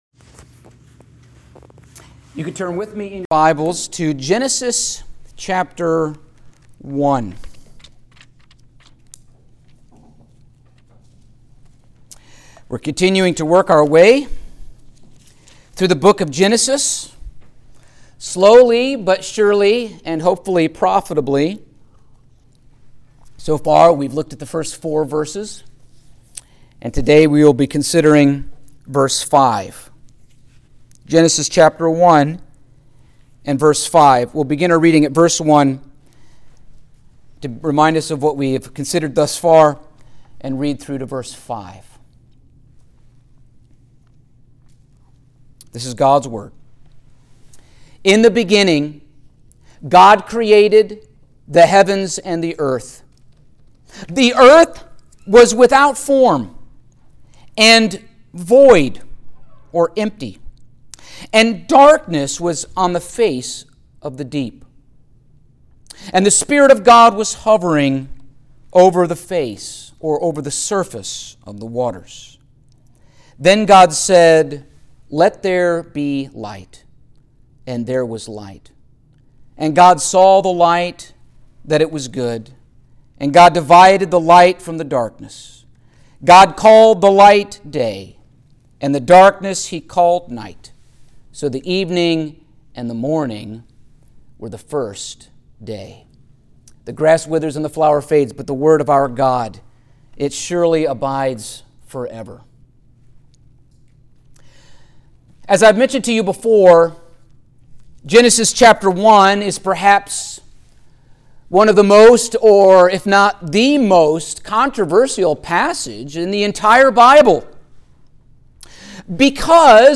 Genesis 1:5 Service Type: Sunday Morning Topics: Creation